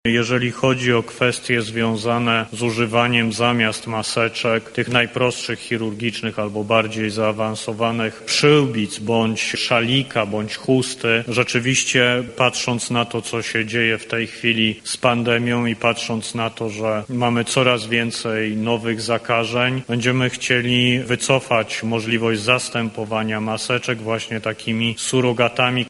Możemy spodziewać się jednak zmian w kwestii zasłaniania ust i nosa o czym mówi minister zdrowia Adam Niedzielski: